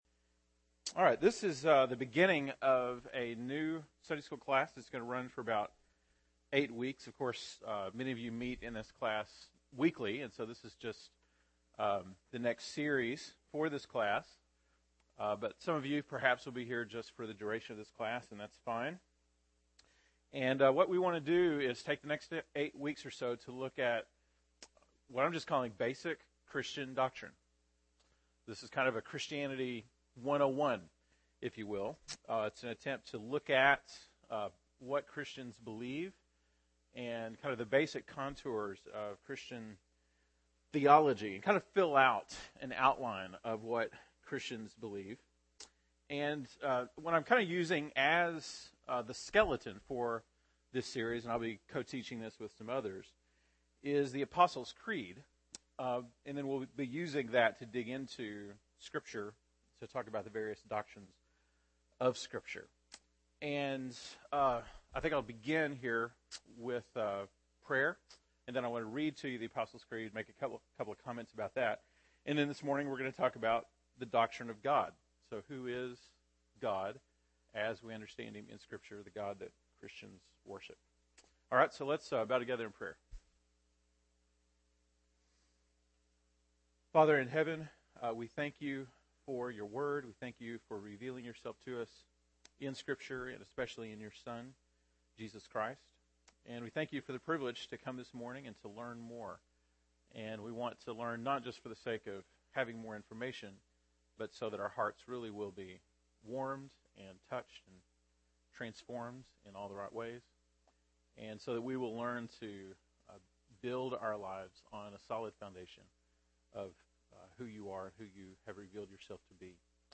February 3, 2013 (Sunday School)